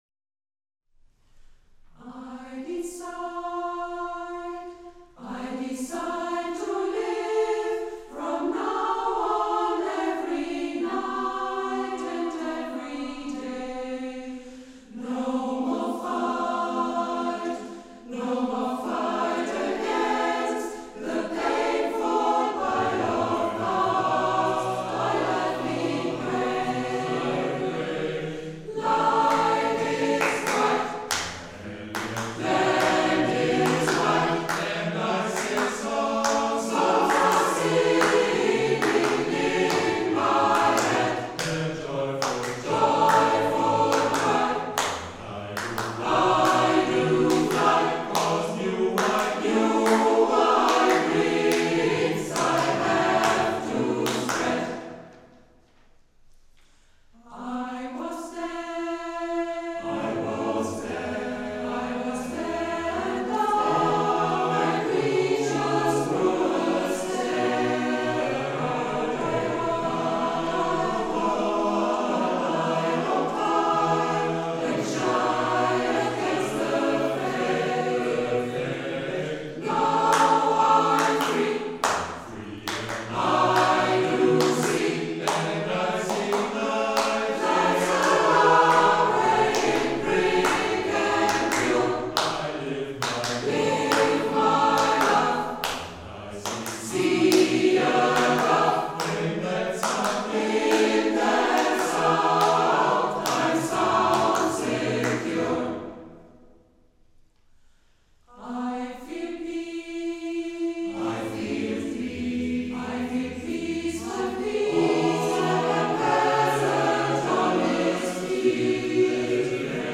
Wir sind der Oldenburger Laienchor „Allegro Vocale“ und bieten ein breites Gesangsspektrum aus Klassik, Gospel, geistlicher Musik, Swing, Pop und Rock.